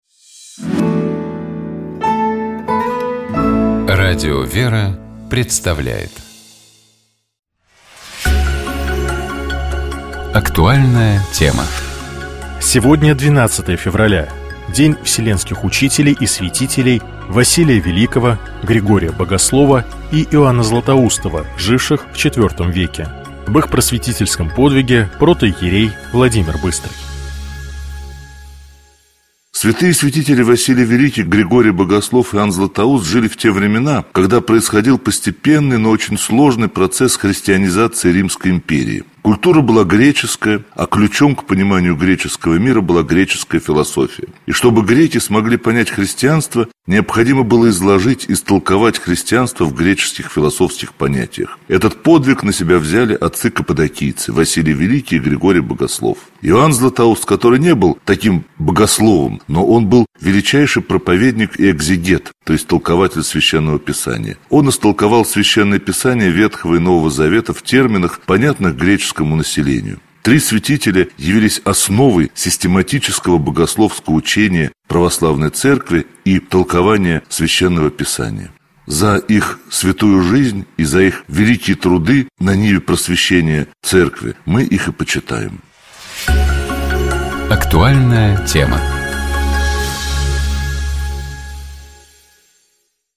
Об их просветительском подвиге — протоиерей